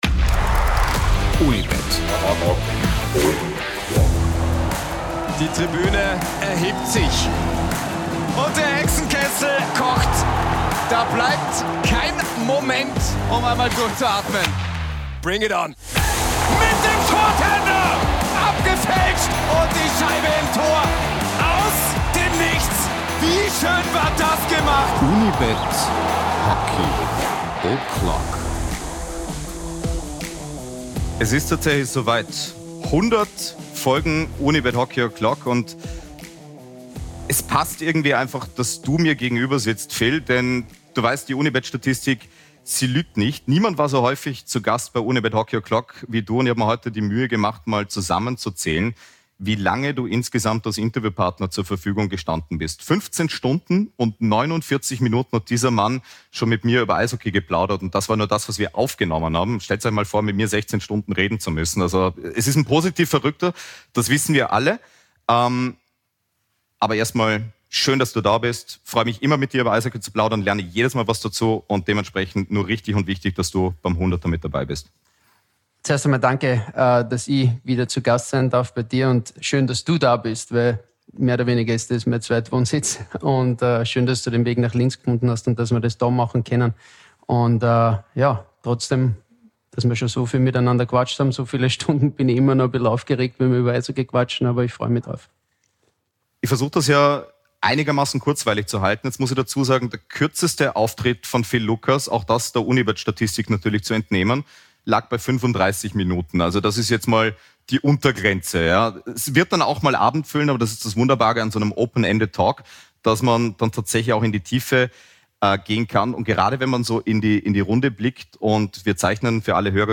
win2day Hockey O'Clock ist der Eishockey-Podcast über das österreichische Eishockey, die win2day ICE Hockey League aber auch internationale Eishockey-Ligen. In ausführlichen Interviews mit Tiefgang kommen Spielerinnen und Spieler, Trainerinnen und Trainer sowie Funktionärinnen und Funktionäre im nationalen und internationalen Umfeld zu Wort.